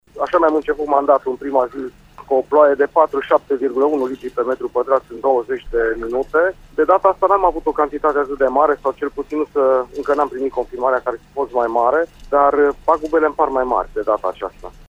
Primarul municipiului Sighișoara, Ovidiu Mălăncrăvean a declarat în emisiunea „Sens Unic” de la RTM că încă din prima zi de mandat a fost nevoit să se confrunte cu inundații: